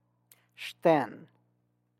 xten[x:tèe’n]